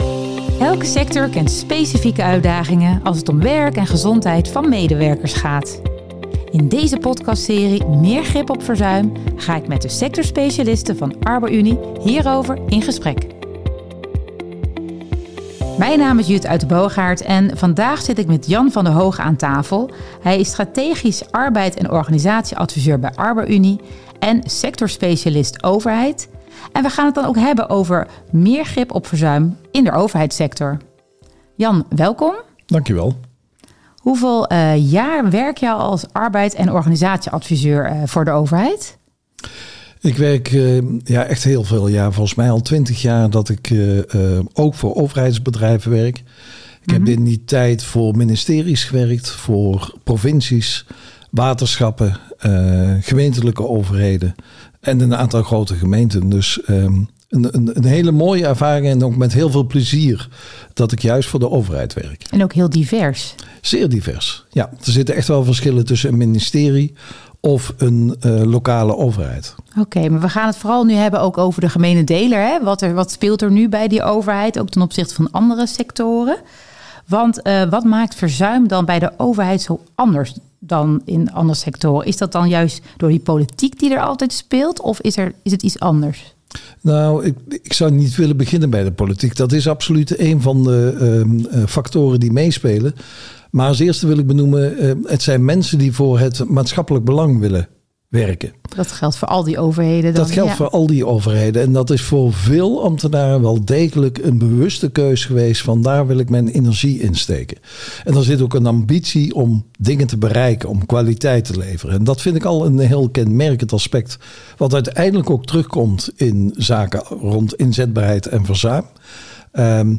Elke sector kent specifieke uitdagingen als het om werk en gezondheid van medewerkers gaat. In deze podcastserie "Meer grip op verzuim" gaan we in gesprek met verschillende sectorspecialisten over de aanpak van verzuim in hun sector.